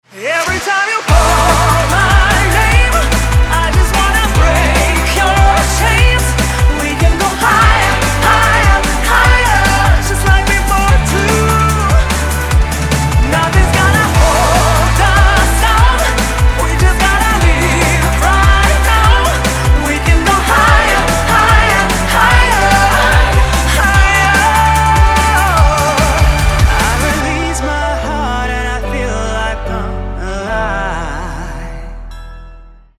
dance
drum n bass